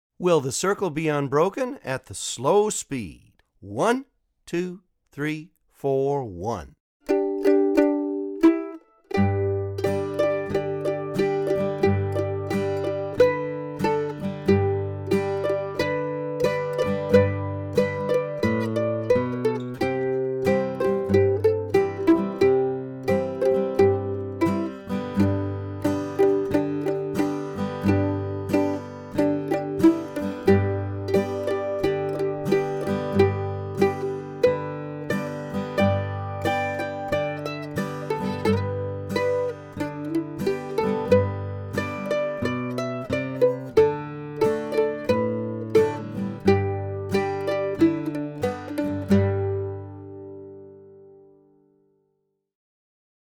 DIGITAL SHEET MUSIC - MANDOLIN SOLO
Favorite Mandolin Solo
Online Audio (both slow and regular speed)